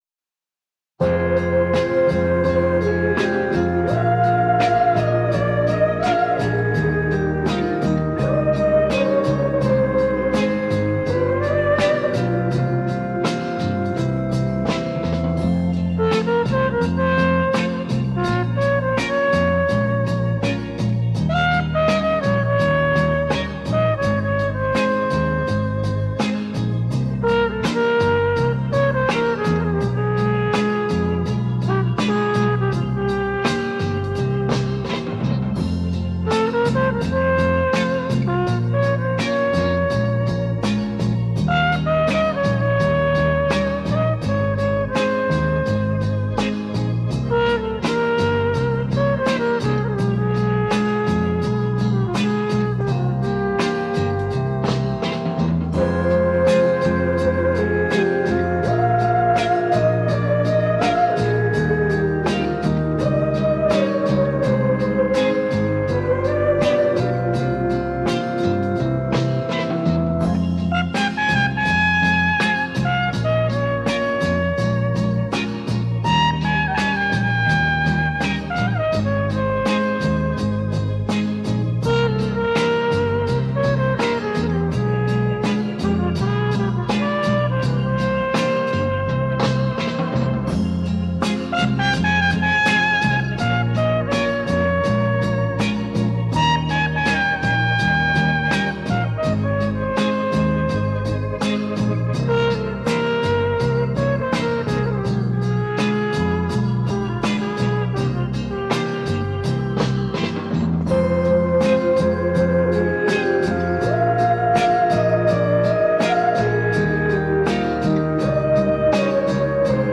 Жанр: Score